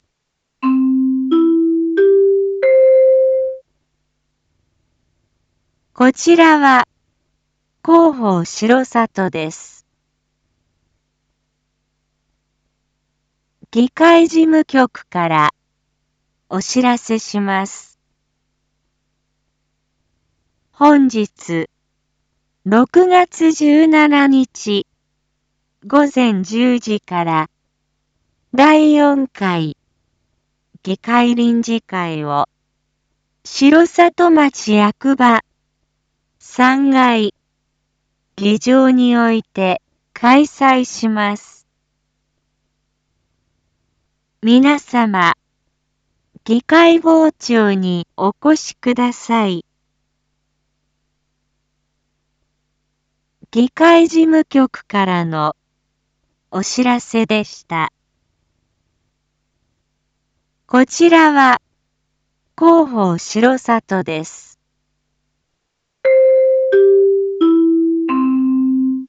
Back Home 一般放送情報 音声放送 再生 一般放送情報 登録日時：2025-06-17 07:01:10 タイトル：②第４回議会臨時会 インフォメーション：こちらは広報しろさとです。